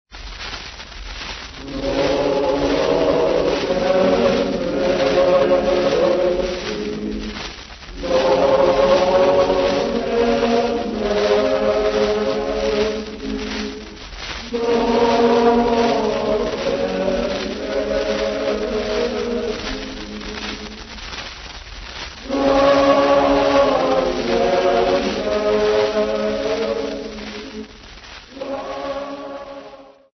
Students of the college of the Ressurection of St Peter
Folk Music
Field recordings
Africa South Africa Johannesburg f-sa
sound recording-musical
Indigenous music